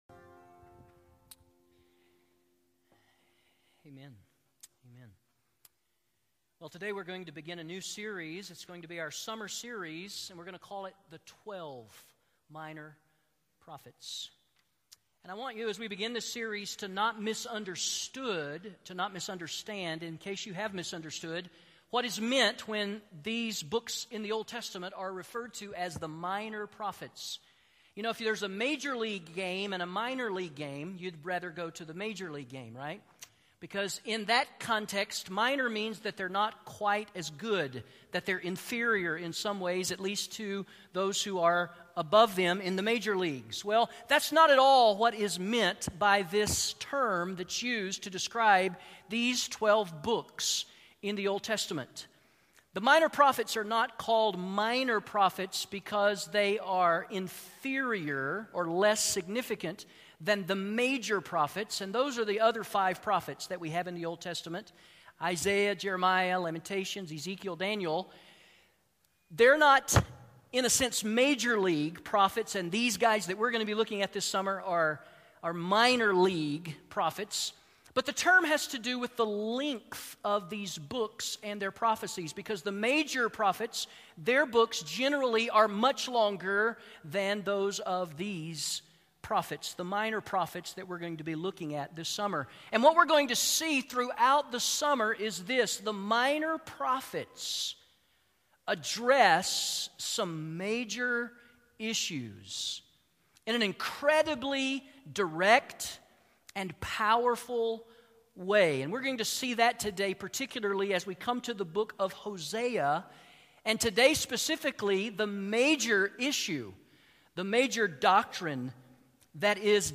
Sermons Archive - Page 29 of 118 - South Canyon Baptist Church